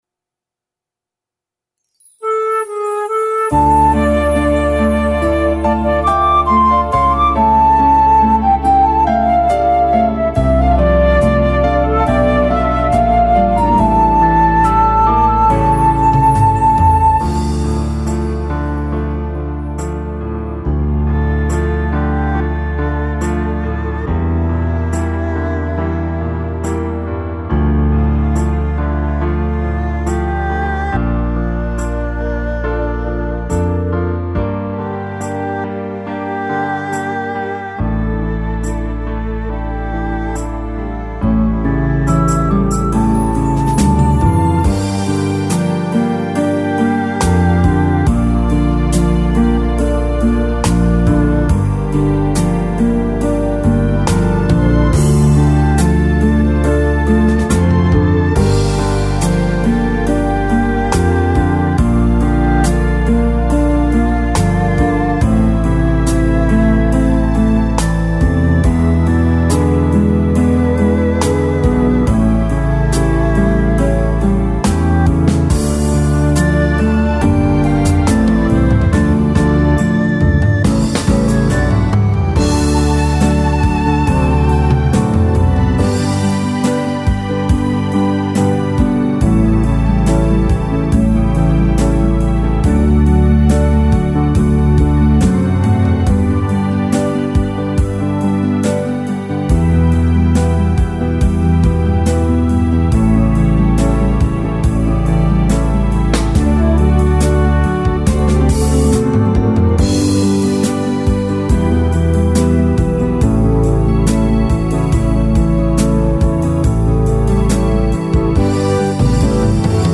Tone Tốp Ca: Dm / Nam: Am
•   Beat  01.